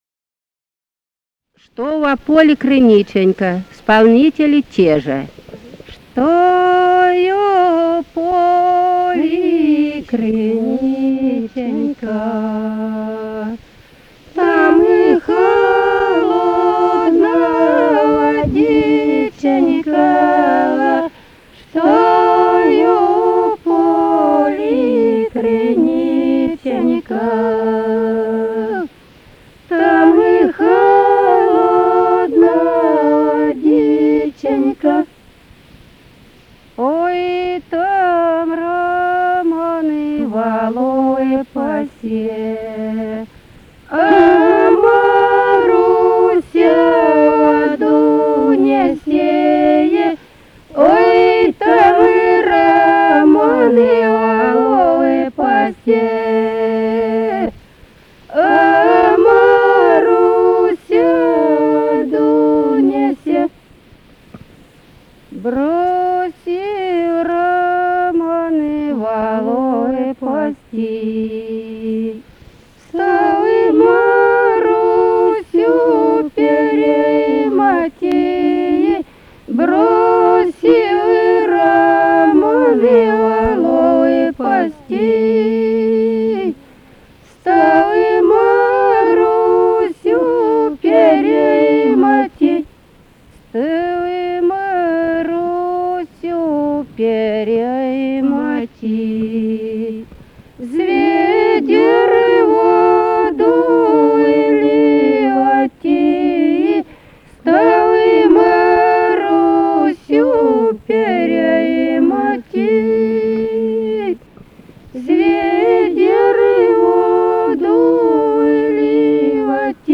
Музыкальный фольклор Климовского района 059. «Что во поле криниченька» (беседная).
Записали участники экспедиции